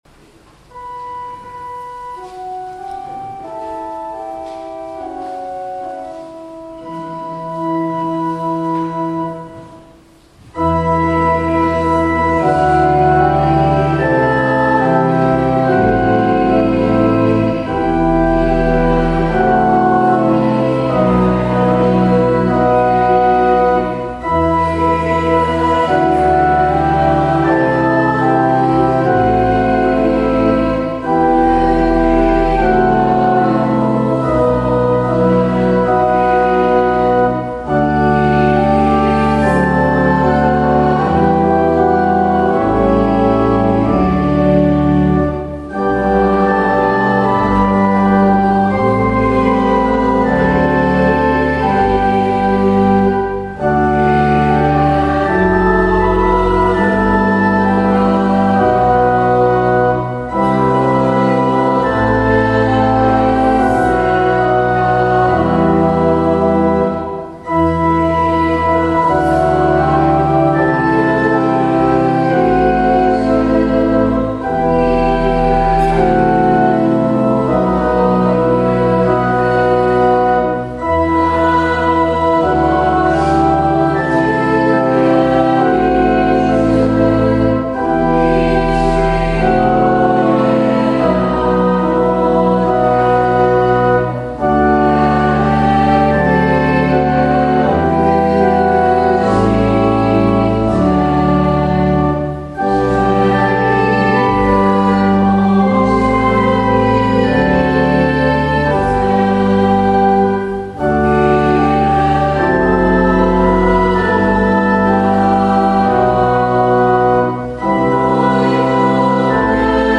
2025. április 18. Passióolvasó istentisztelet